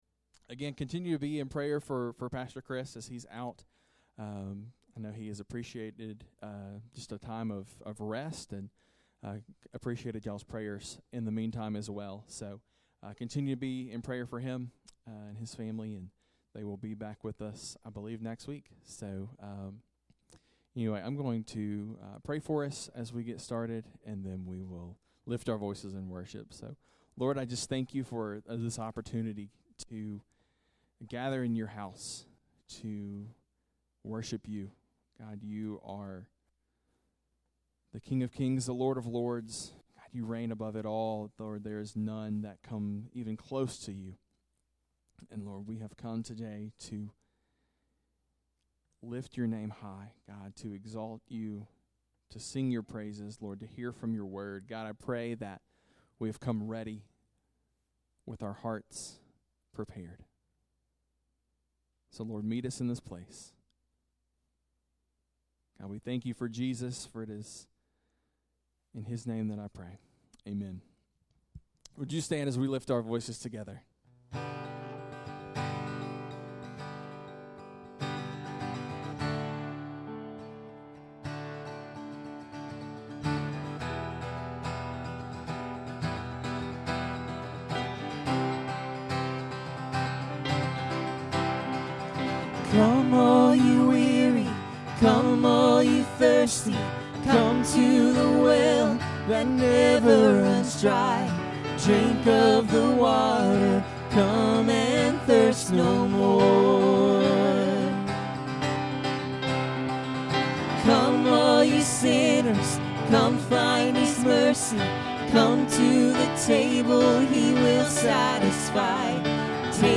Sunday Morning Sermon June 8, 2025